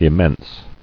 [im·mense]